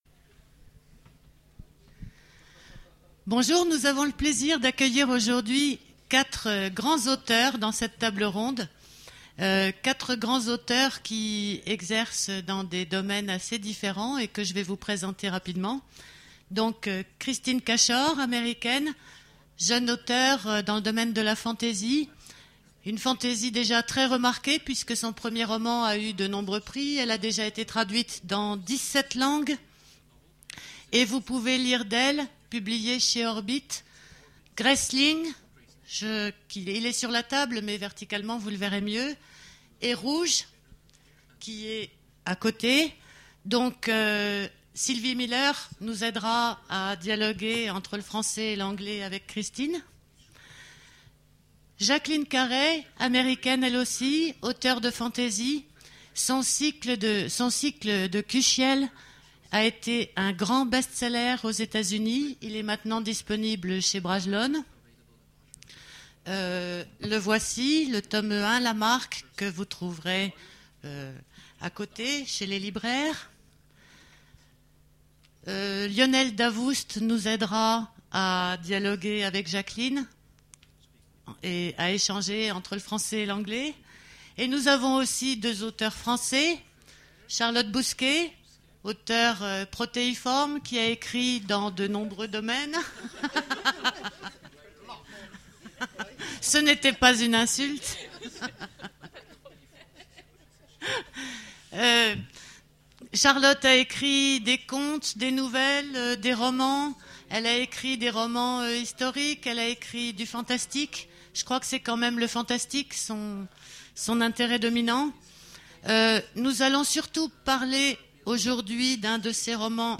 Voici l'enregistrement de la conférence Héroïnes d'exception aux Imaginales 2010